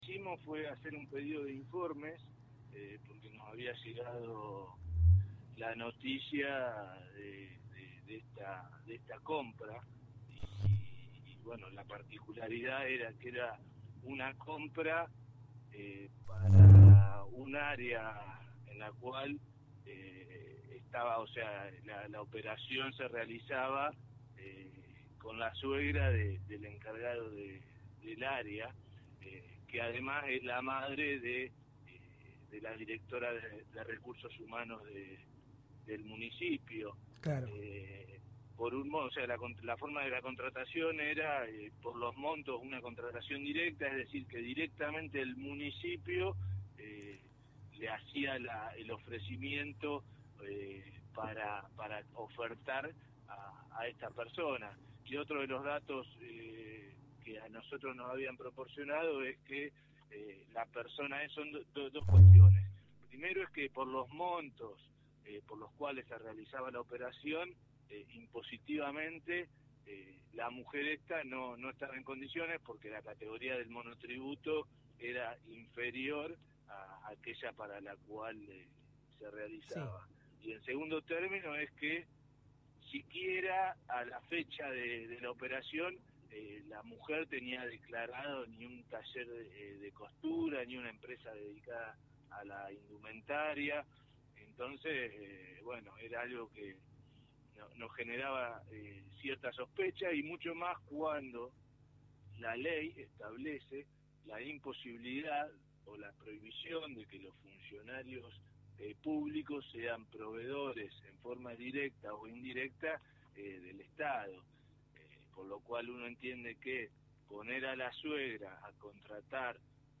El Concejal Ramiro Llan de Rosos , hablo con Radio Verdad fm 99.5, escucha la nota aquí: